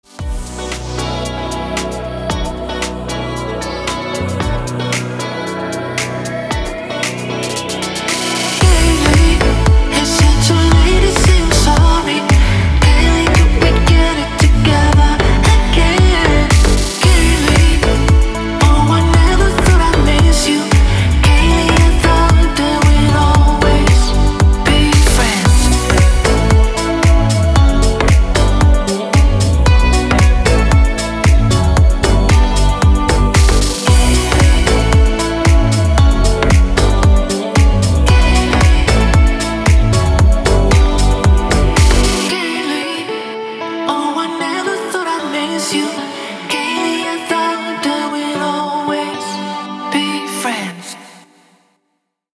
• Качество: 320, Stereo
гитара
мужской вокал
deep house
электронная музыка
расслабляющие
Спокойная танцевальная мелодия...